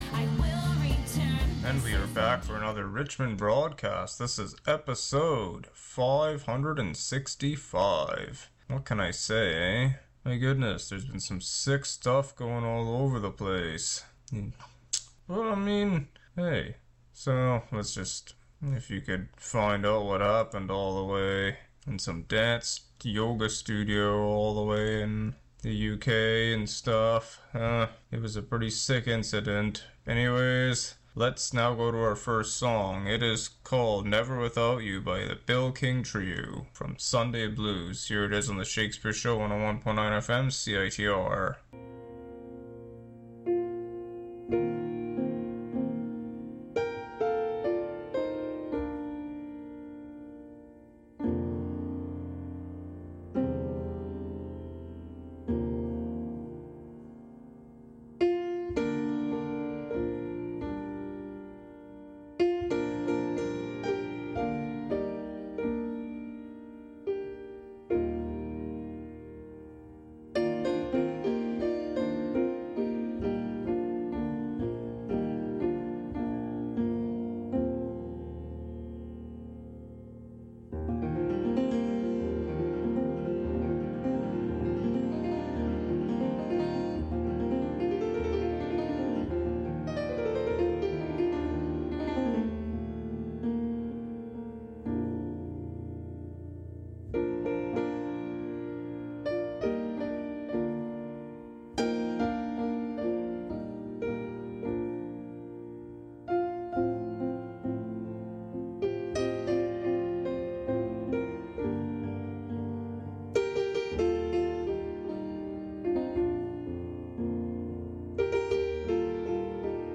an eclectic mix of music